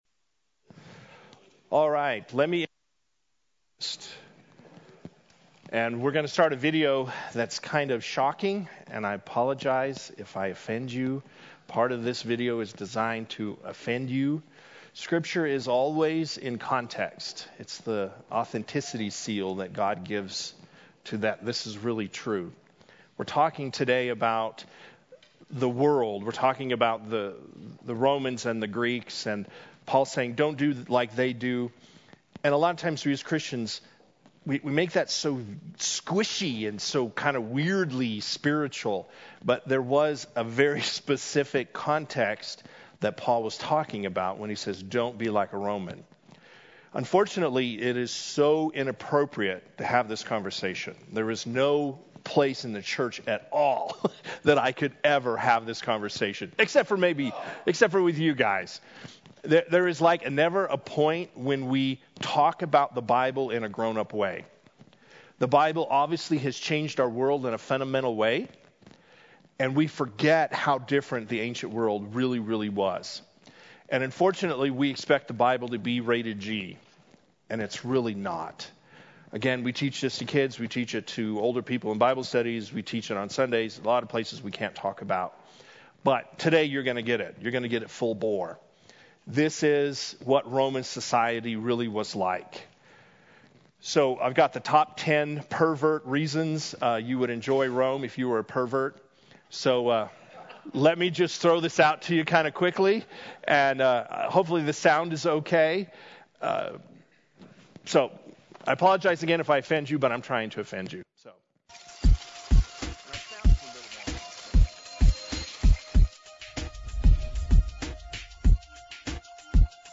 Men’s Breakfast Bible Study 11/3/20